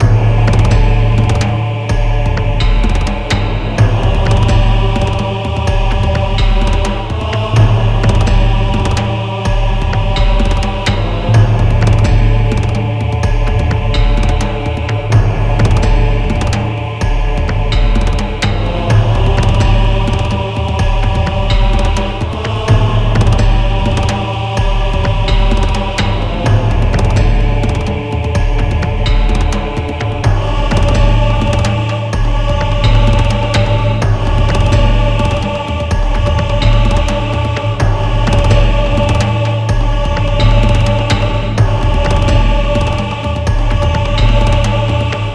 Instrument: Drums
Serious